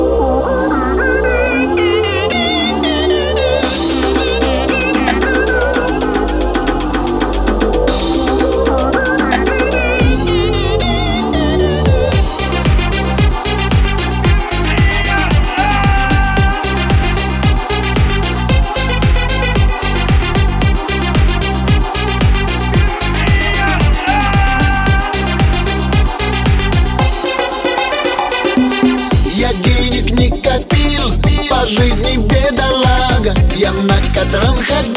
Жанр: Русская поп-музыка / Русский рок / Русские